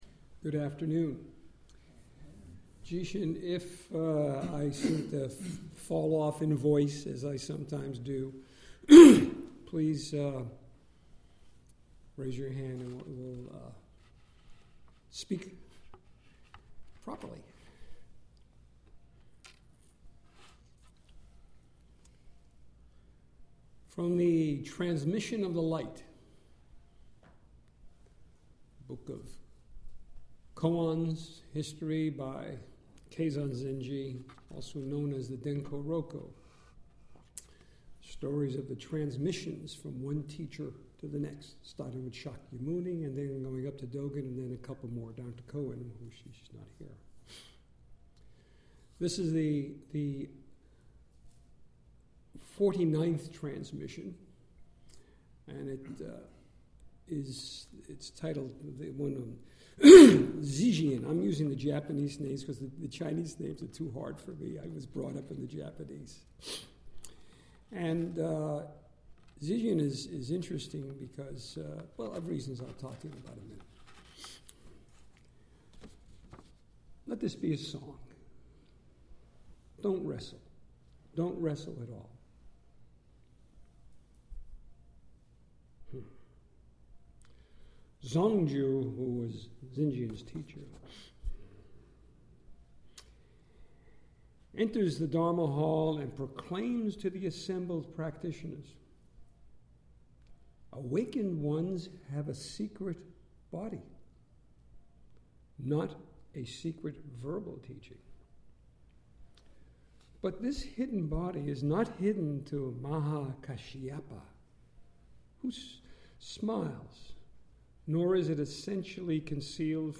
Nothing is Hidden : Dharma Talk
April 2014 at Southern Palm Zen Group